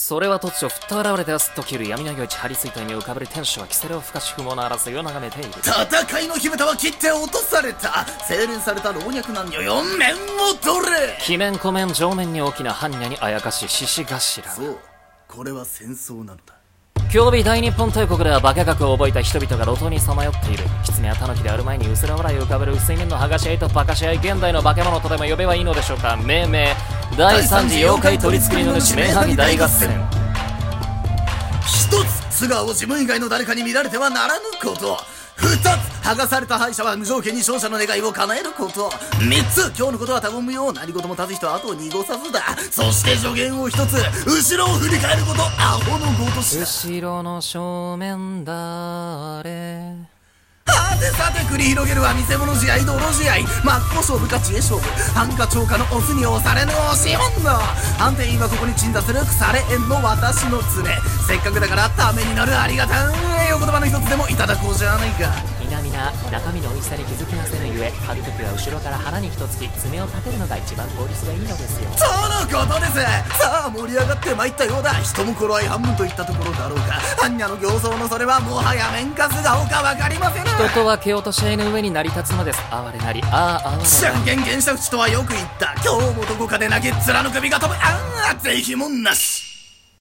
CM風声劇「第参次面剥合戦」